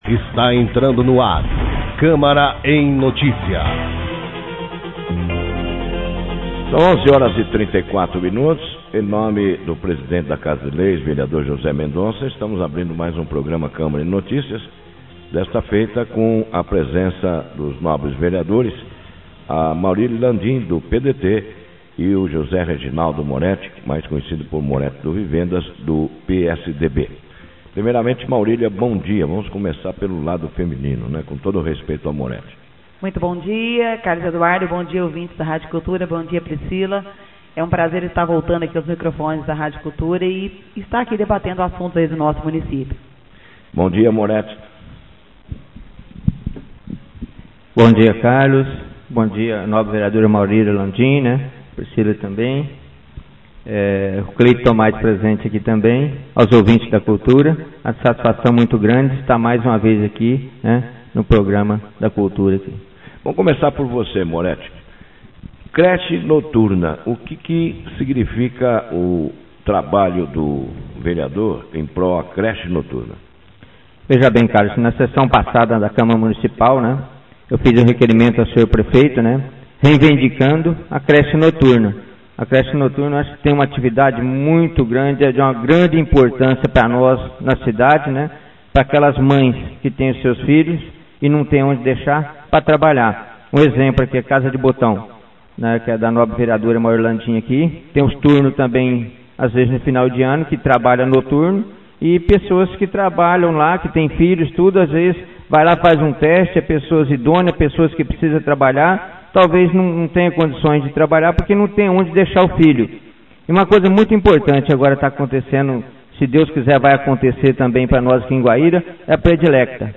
Os vereadores José Antônio Lopes (PSB) e José Reginaldo Moreti (PSDB) foram os entrevistados do último sábado no programa Câmara em Notícias da rádio Cultura AM.